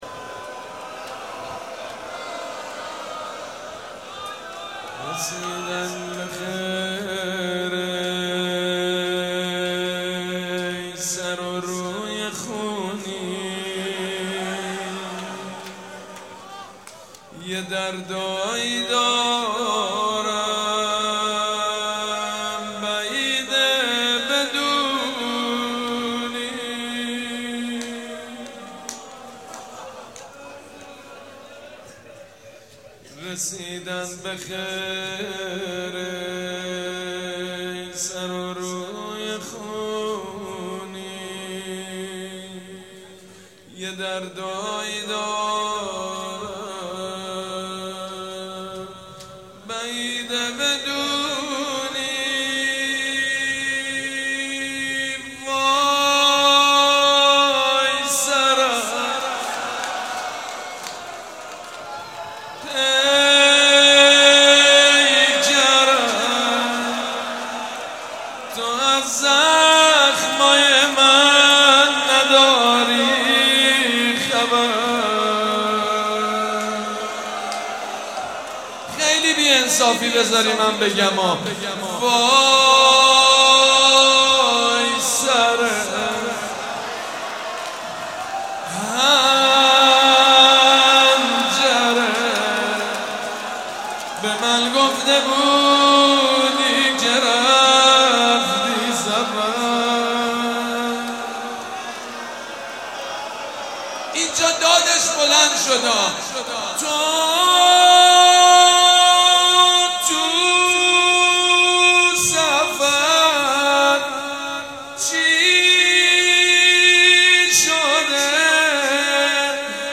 مداحی شب سوم محرم 96 با نوای مداحان اهل بیت (ع)
روضه